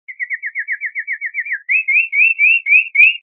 スズメ目アトリ科の小鳥で鳴き声が凄く綺麗で心を和ませてくれます。
カナリアの鳴き声02 着信音